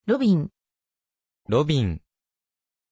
Aussprache von Robbin
pronunciation-robbin-ja.mp3